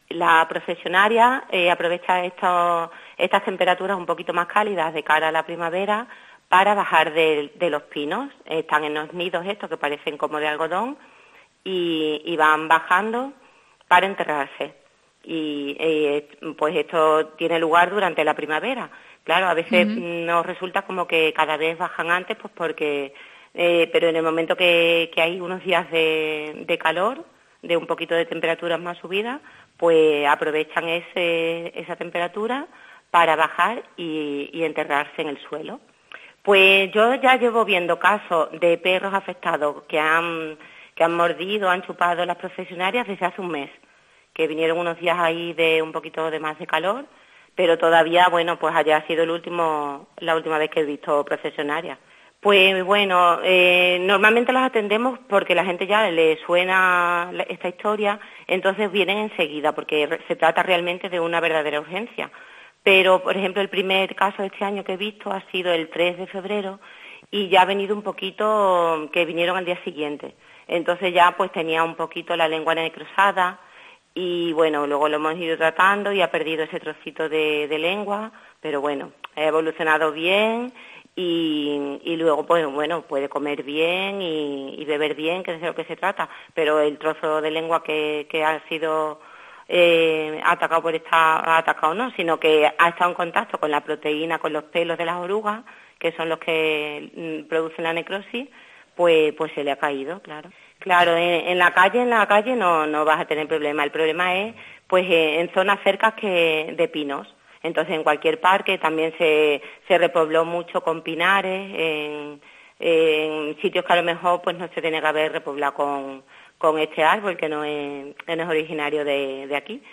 Veterinaria. Nos habla de la Oruga Procesionaria